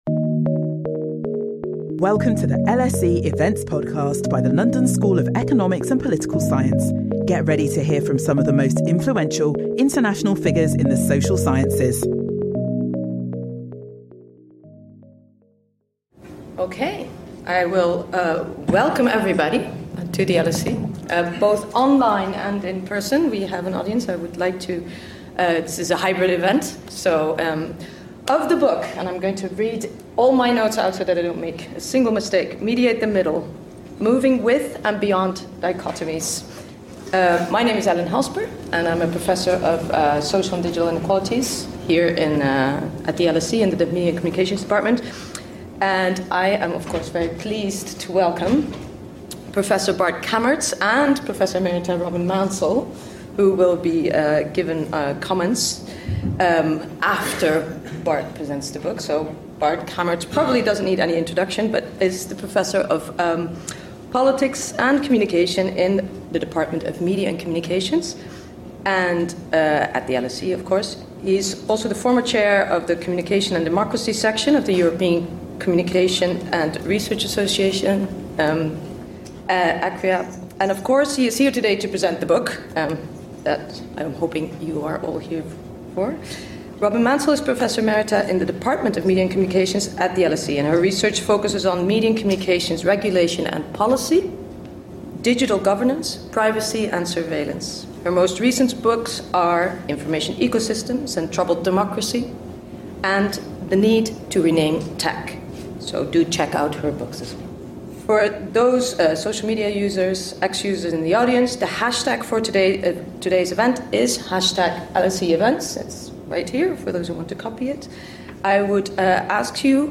Contributor(s): Professor Valerie Ramey | Join us for the 2025 Economica-Phillips Lecture which will be delivered by Valerie Ramey.